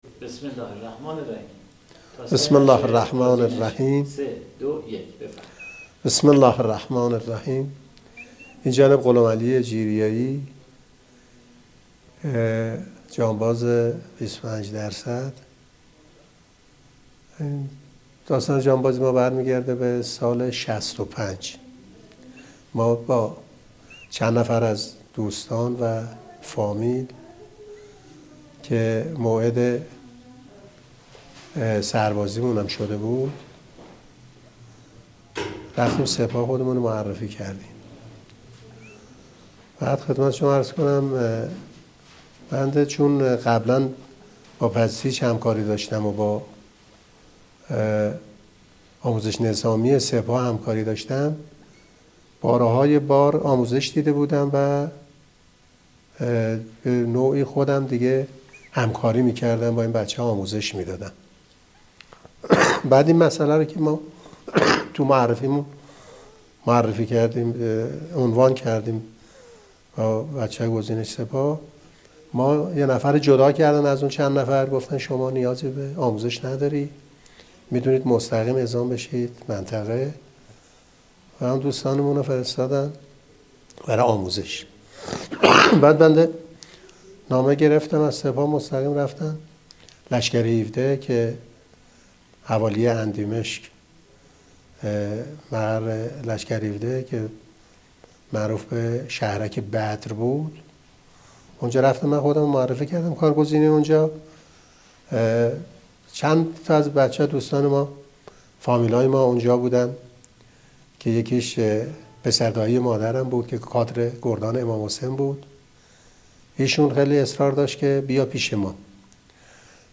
در گفت‌وگو با نوید شاهد استان مرکزی